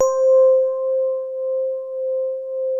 E-PIANO 1
TINE SOFT C4.wav